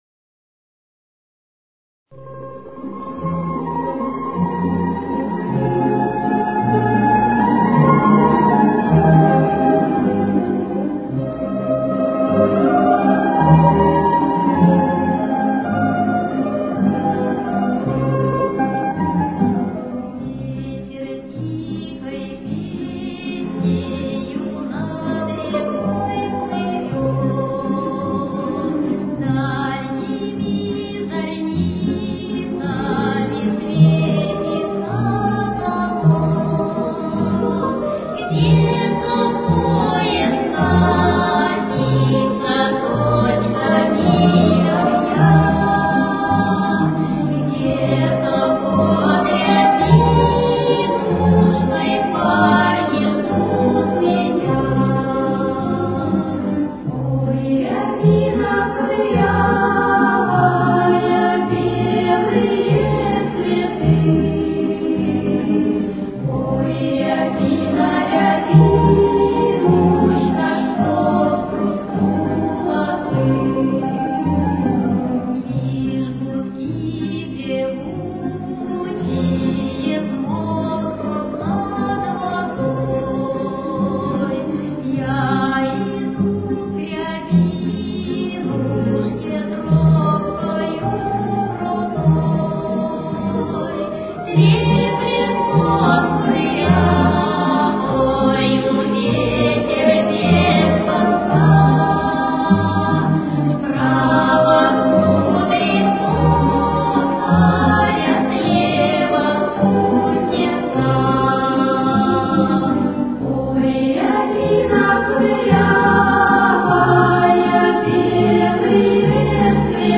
Темп: 162.